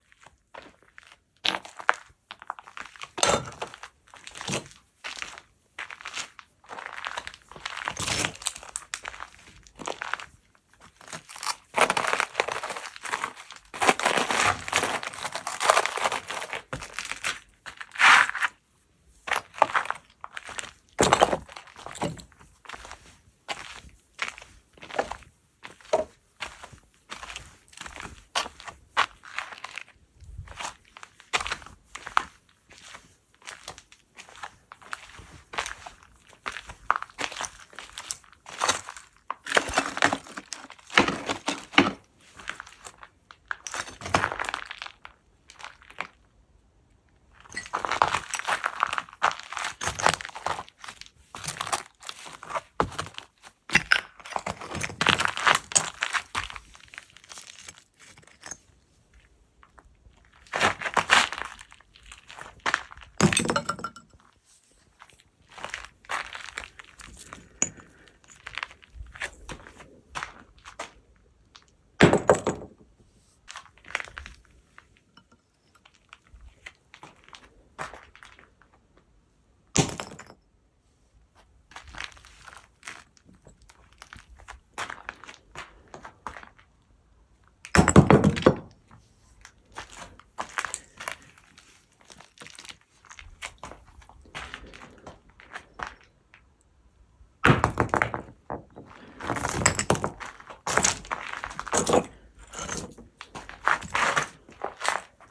There is some construction going on at the house so naturally I had to play in it at the end of the day. Walking around, dropping some tiles and bricks, and so on.
Still some pretty pleasant textures I think